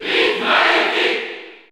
Category: Crowd cheers (SSBU) You cannot overwrite this file.
Dark_Pit_Cheer_French_NTSC_SSBU.ogg